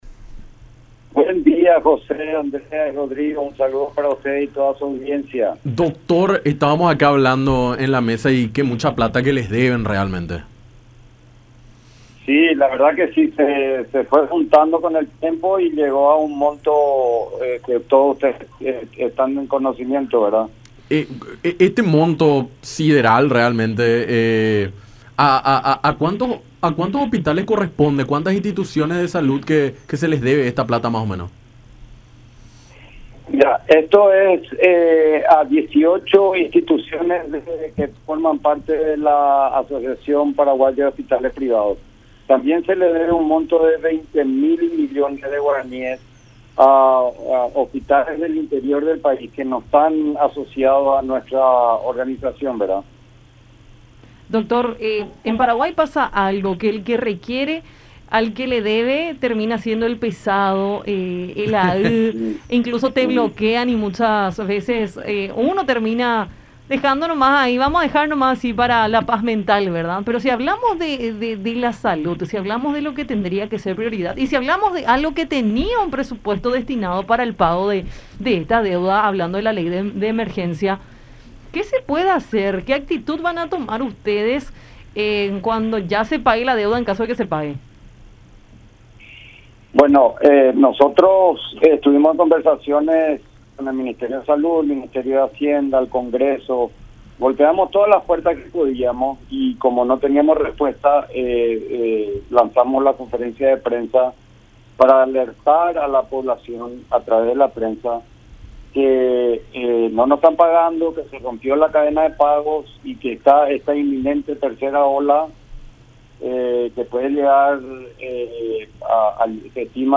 en conversación con Enfoque 800 a través de La Unión.